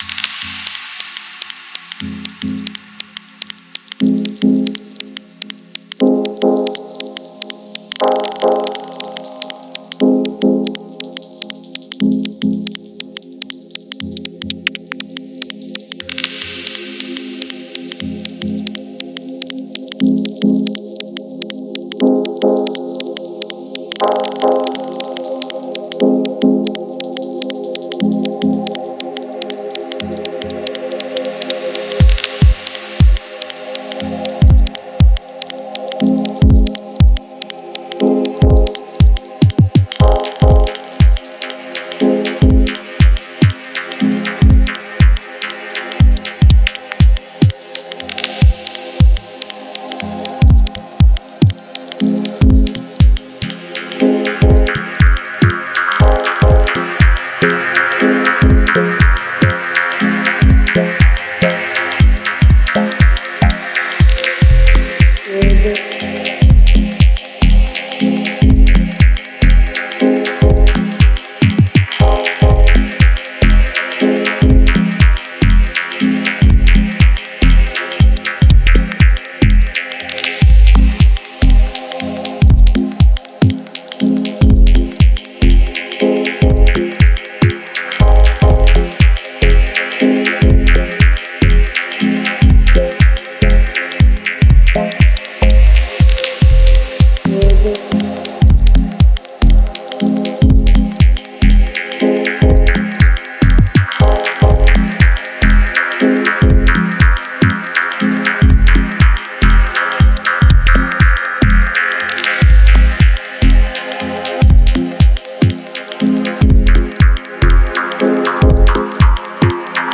Genre: Deep Dubstep, Dub, Electronic.